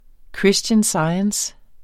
Udtale [ ˈkɹisdjən ˈsɑjəns ]